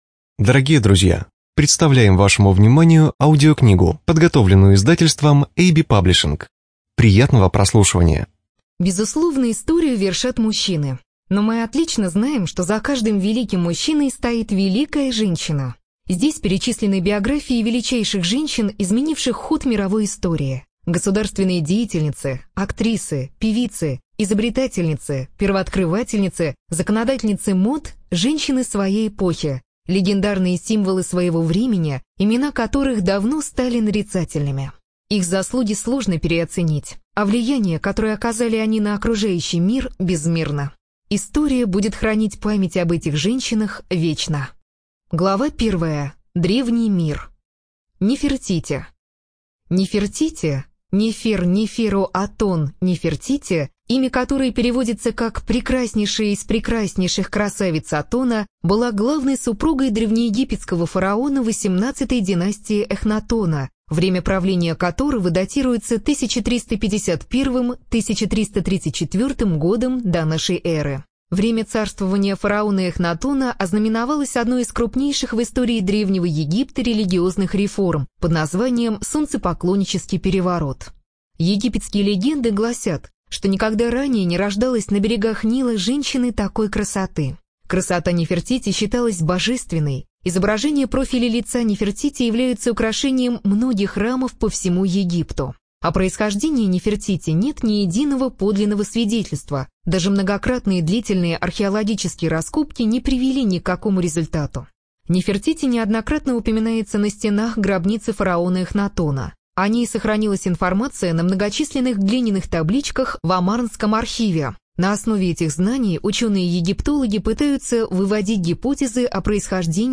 Студия звукозаписиAB-Паблишинг